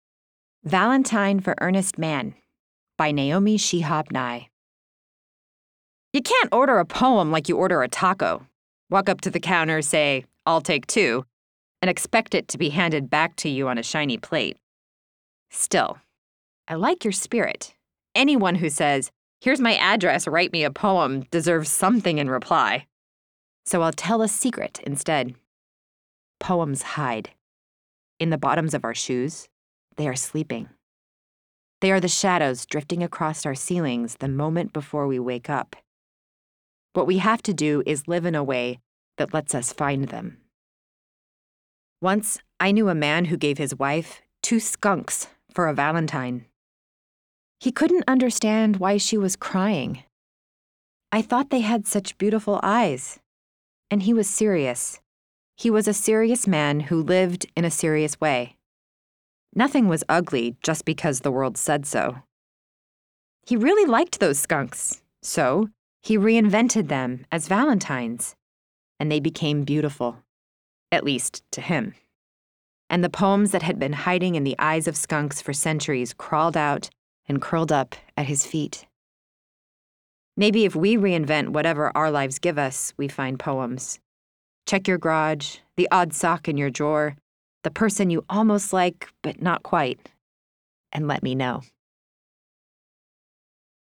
read aloud.